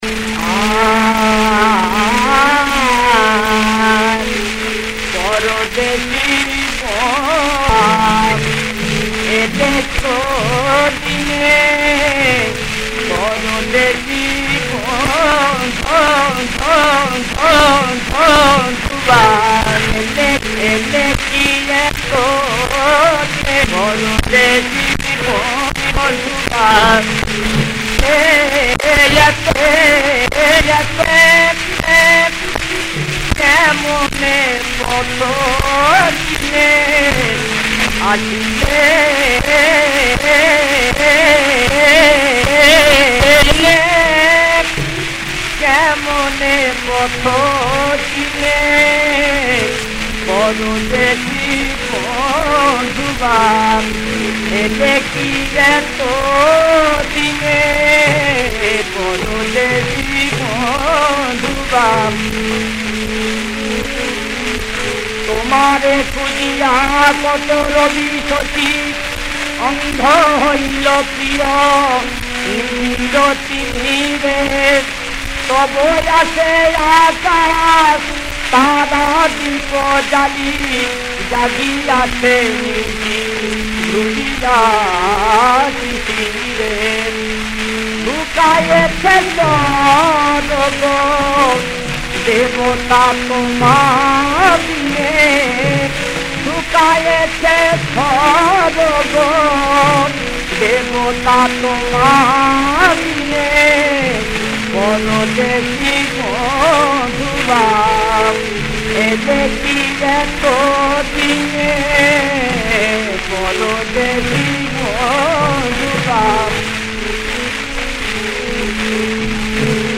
শিল্পী: গণি মিয়া (ধীরেন দাস)।]
• বিষয়াঙ্গ: ভক্তি (ইসলামী)
• তাল: দাদরা
• গ্রহস্বর: পা